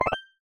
Menu_Navigation03_Open.wav